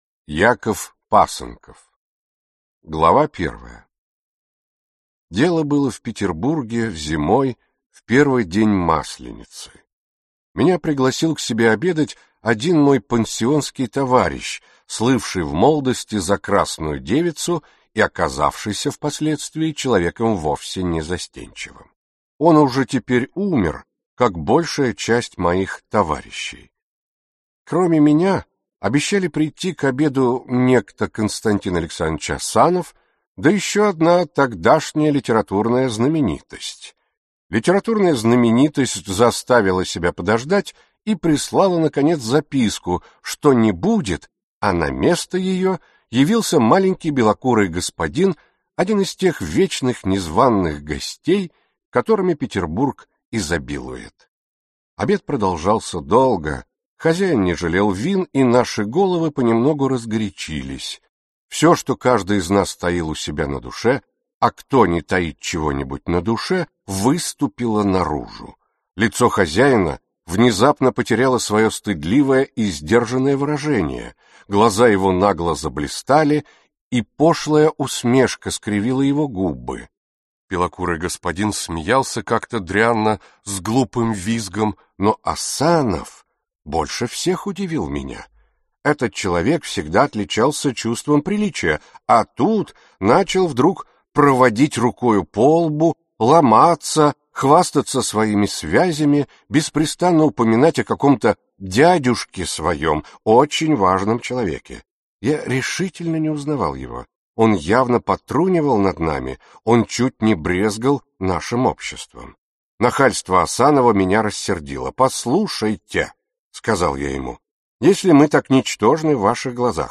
Аудиокнига Яков Пасынков | Библиотека аудиокниг